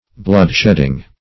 Bloodshedding \Blood"shed`ding\, n.
bloodshedding.mp3